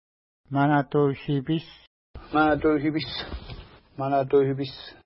Manatueu-shipiss Next name Previous name Image Not Available ID: 487 Longitude: -60.2774 Latitude: 53.2779 Pronunciation: ma:na:twew-ʃi:pi:s Translation: Swearing River (small) Official Name: Traverspine River Feature: river